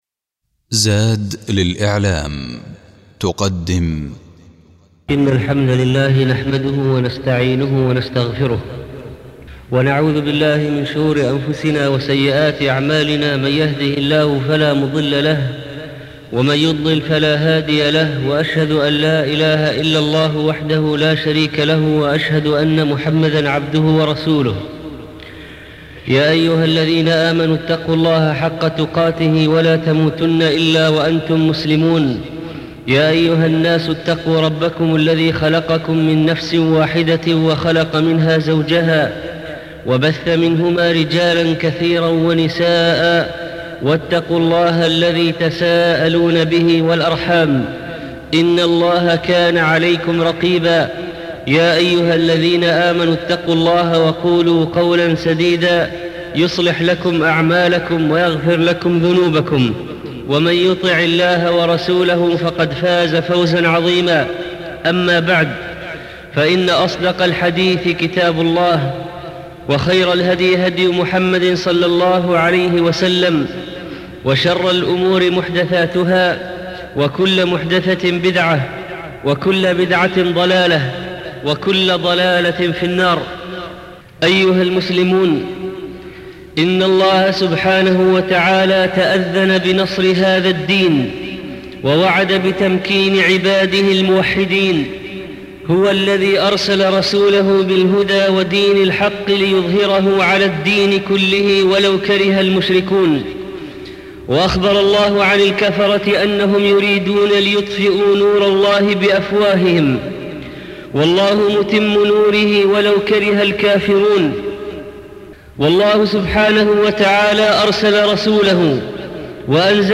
الاستماع تحميل تحميل ملف صوتي تحميل ملف نصي 12 ربيع الأوّل 1420 التعليقات: 1 الزيارات: 29740 التحميل: 5526 كيف تدعو نصرانياً للإسلام؟ 1 عناصر المادة الخطبة الأولى مبشرات النصر متى نستحق النصر؟